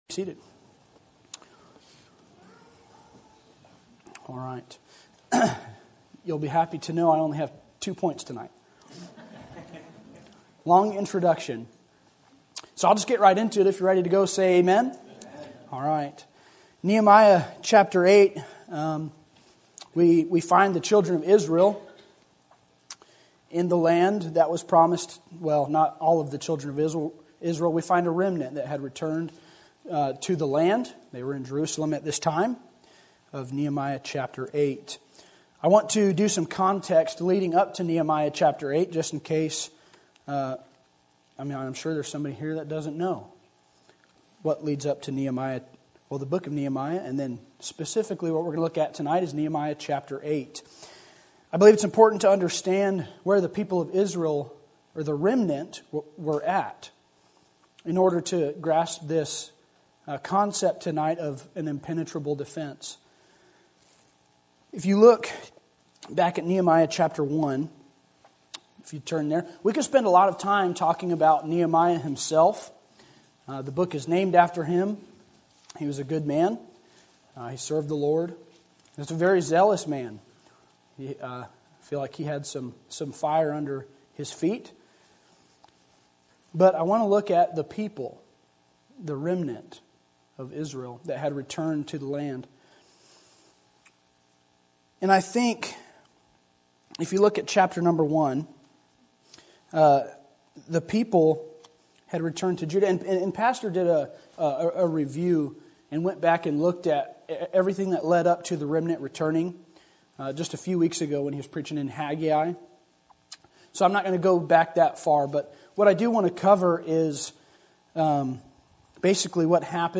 Sermon Topic: General Sermon Type: Service Sermon Audio: Sermon download: Download (21.68 MB) Sermon Tags: Nehemiah Joy Strength Word